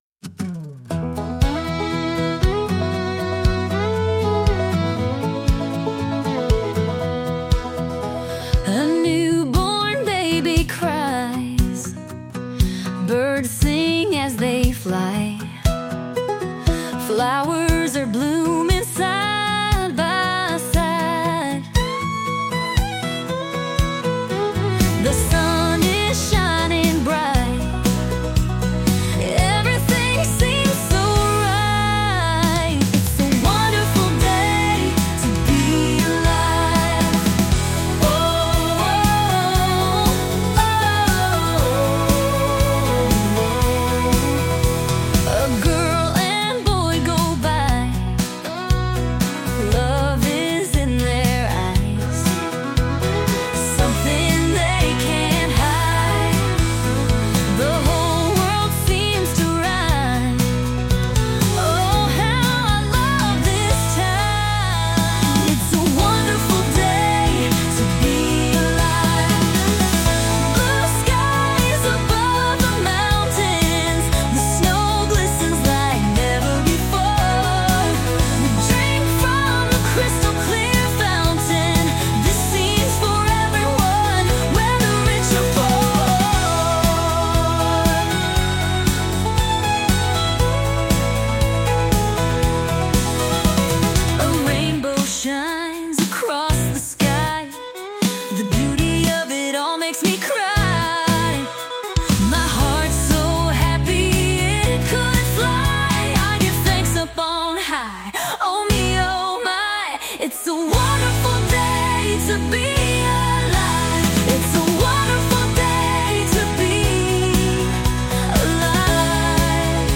female led Country